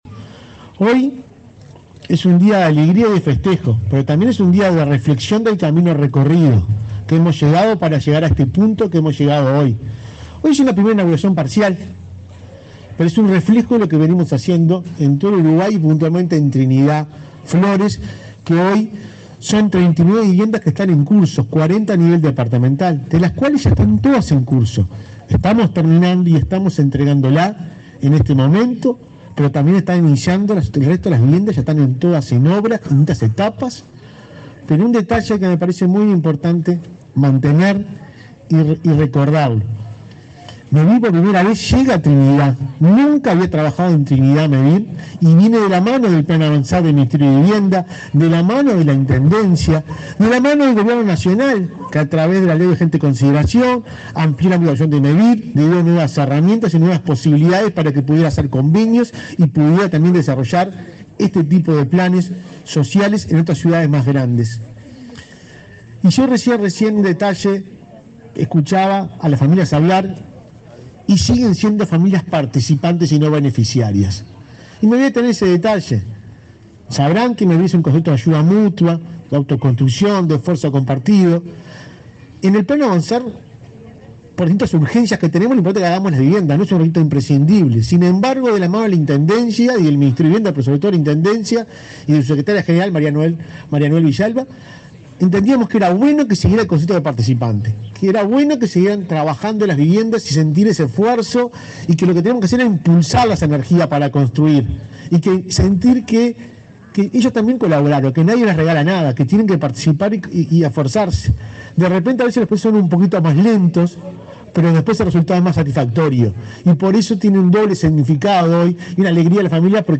Palabras de autoridades en inauguración de viviendas en Flores
El presidente de Mevir, Juan Pablo Delgado; el ministro de Vivienda, Raúl Lozano, y el secretario de la Presidencia, Álvaro Delgado, participaron en